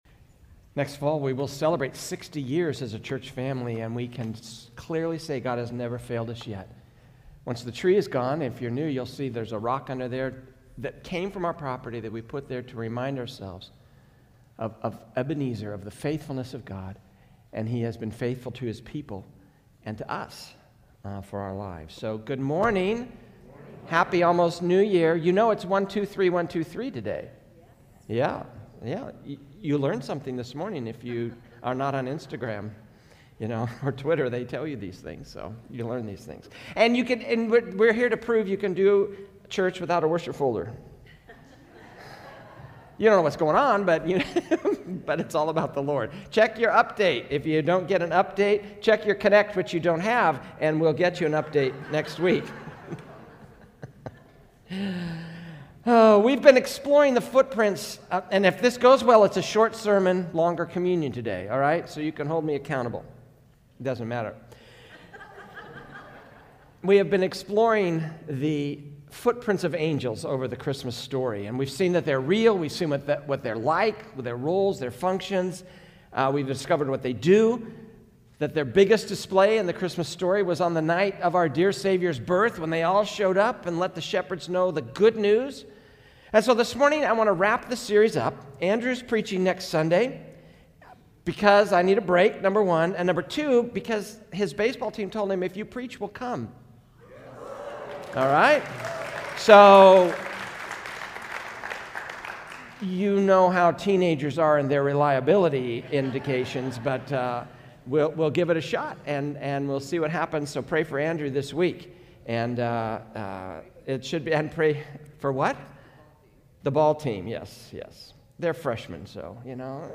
A message from the series "The Unexpected King."